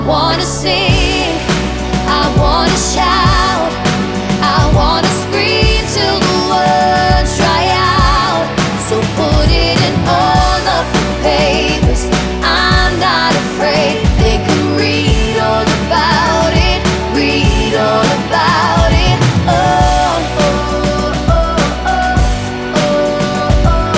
For Solo Male R'n'B / Hip Hop 3:51 Buy £1.50